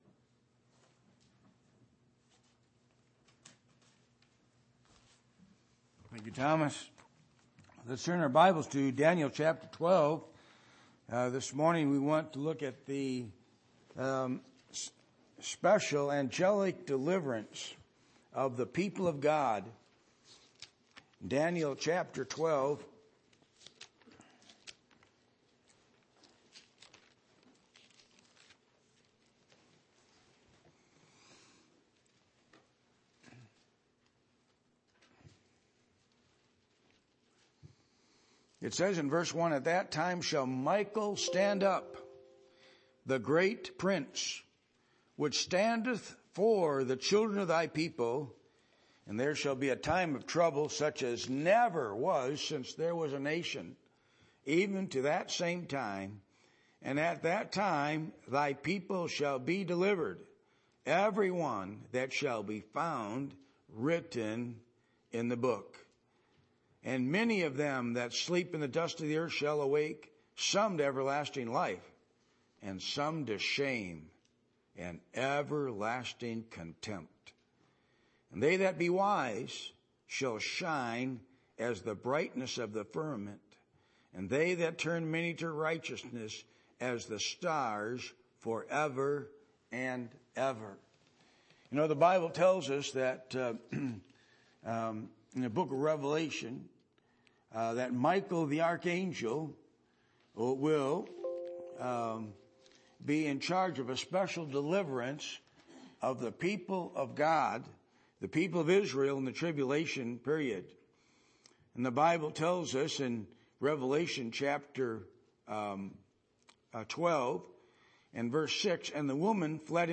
Daniel 12:1-13 Service Type: Sunday Morning %todo_render% « Is God’s Blessing on Your Life?